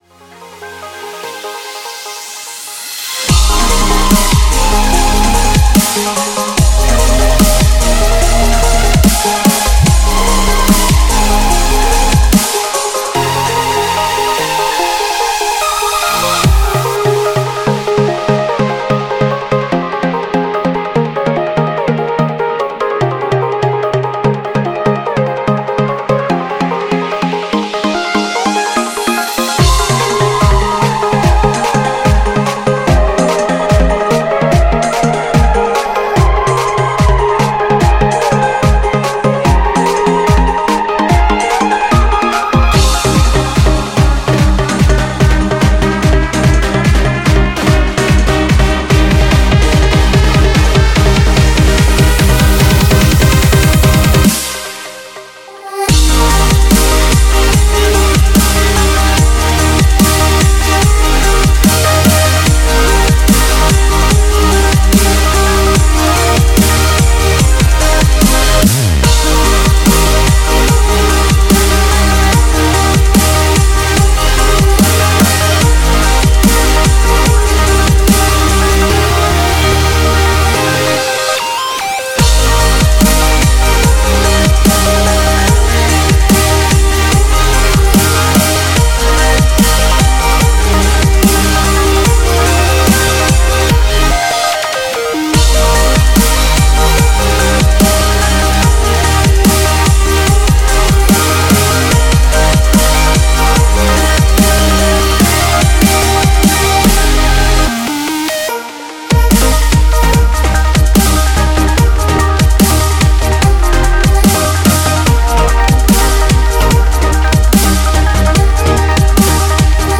BPM146
Audio QualityPerfect (High Quality)
Comments[CHILLSTEP]